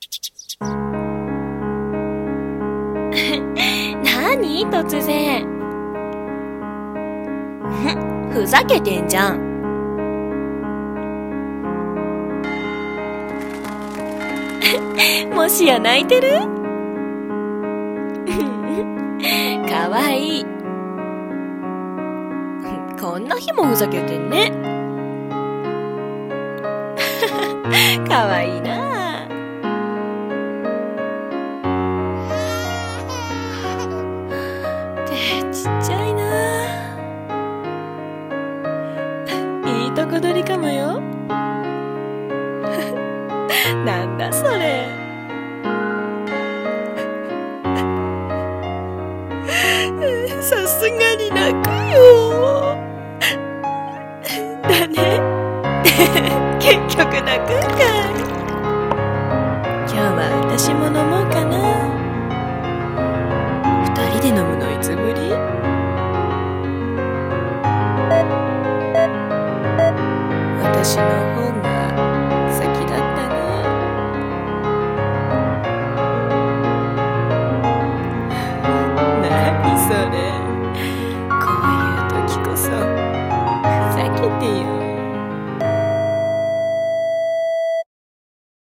【声劇】最期に思い出すのは。